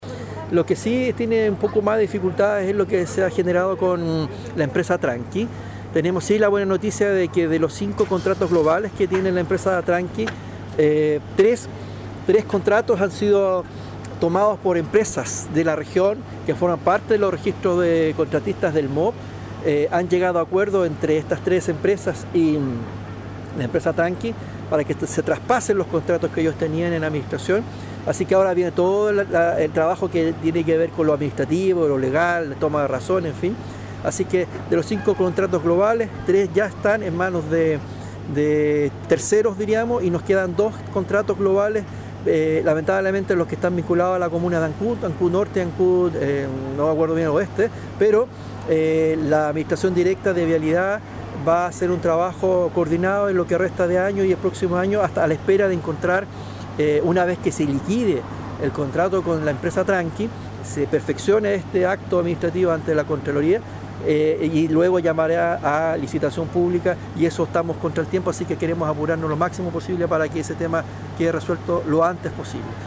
En esta misma información, y confirmando que se ha logrado avanzar en recuperar los trabajos de la empresa global de conservación de caminos en gran parte de Chiloé, el seremi de Obras Públicas, Juan Fernando Alvarado, entregó una actualización de la situación que aconteció en la provincia, tras el retiro por insolvencia de la empresa Inversiones Tranqui.